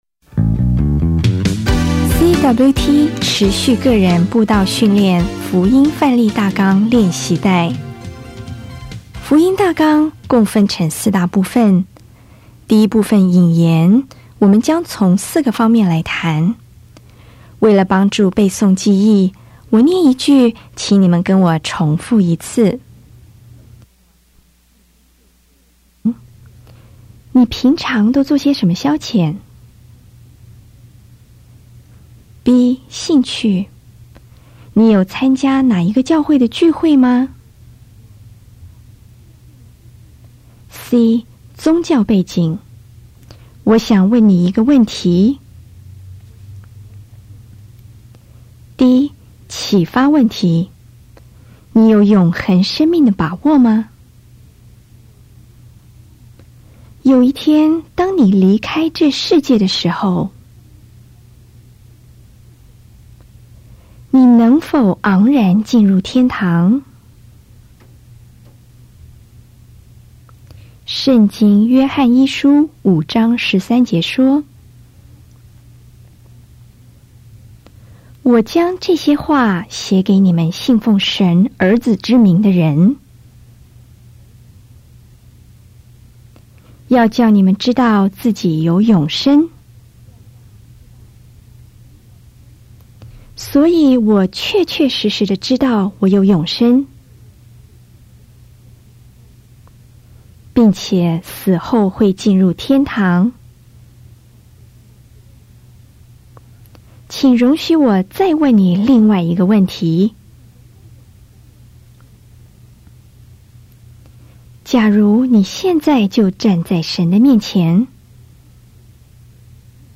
CWT-Training-Class-Audio.mp3